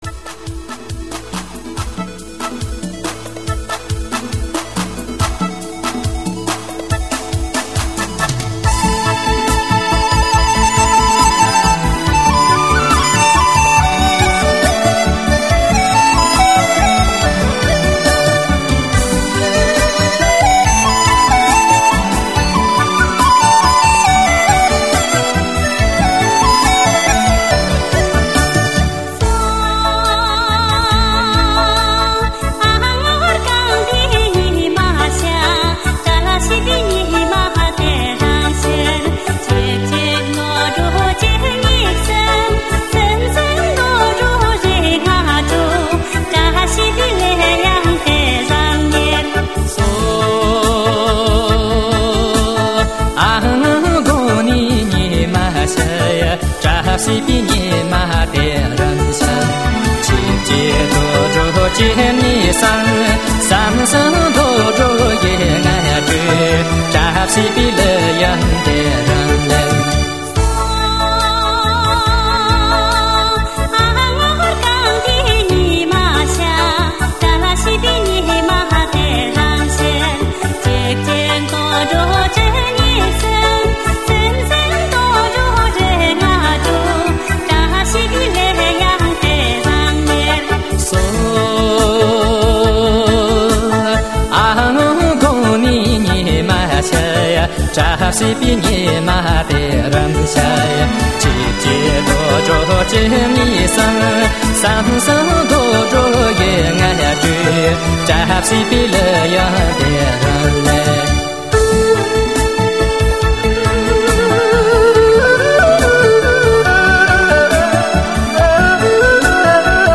羌族舞曲——吉祥的日子